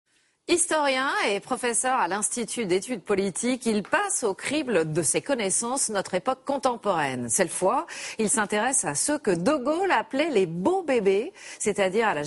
Quel autre symbole de la génération du baby-boom la journaliste évoque-t-elle pour terminer son interview ?